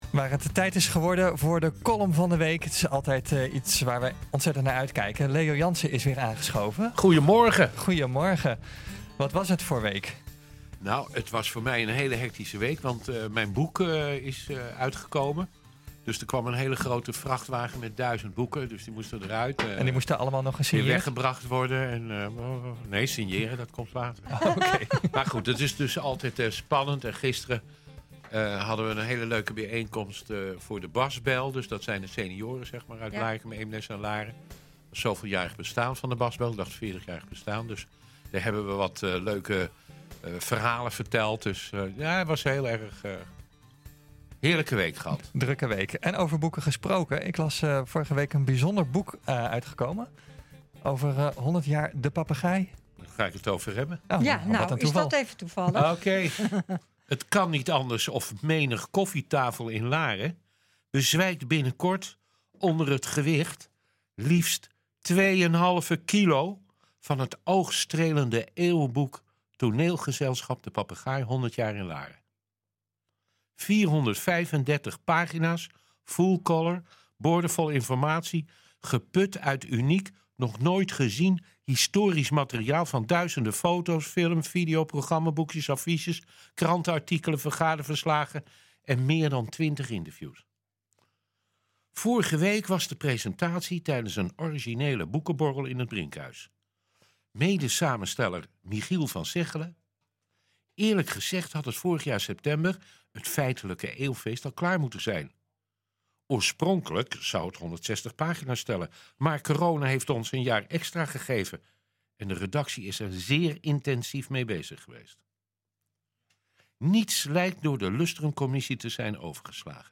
NH Gooi Zaterdag - Column